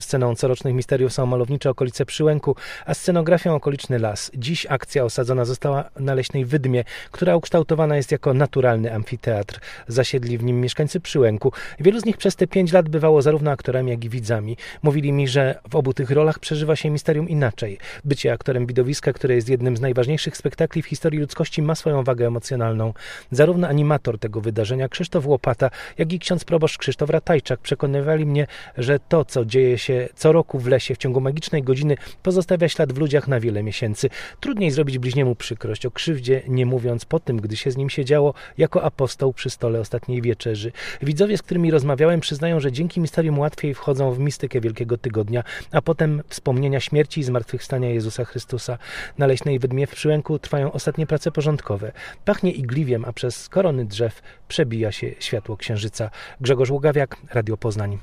Misterium oglądał na żywo reporter Radia Poznań.